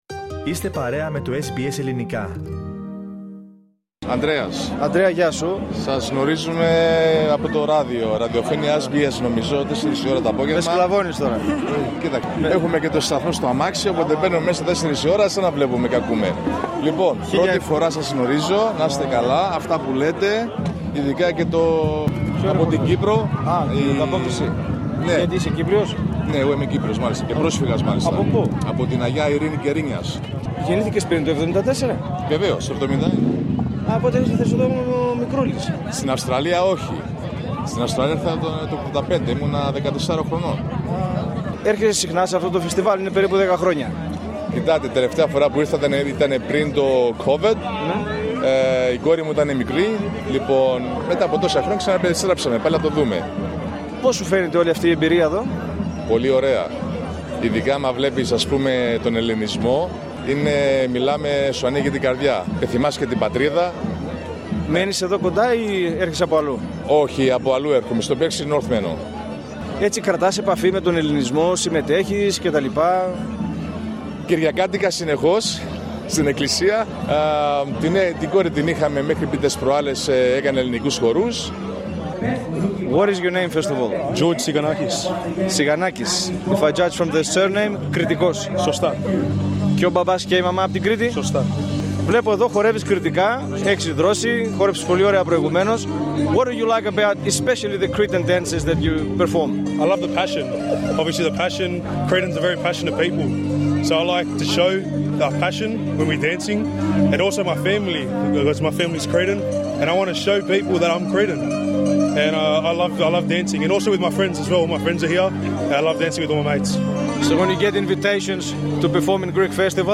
Το μικρόφωνο του SBS Greek τέθηκε στην διάθεση των ακροατών/ακροατριών μας που βρέθηκαν στο πρόσφατο ελληνικό φεστιβάλ, Let’s Go Greek Parramatta.
Εκεί πήραμε το μικρόφωνό μας και περιδιαβαίνοντας στις εγκαταστάσεις, μιλήσαμε, με γνωστούς και αγνώστους, ελληνικής και μη ελληνικής καταγωγής, με άτομα που ήταν σε περίπτερα, με μέλη χορευτικών συγκροτημάτων, με παράγοντες και με επισκέπτες. Αρκετοί φίλες και φίλες μας κατέθεσαν τις απόψεις και τις προτάσεις του για την γιορτή και άλλοι μας μίλησαν για συμμετοχή τους στον ελληνικό χορό.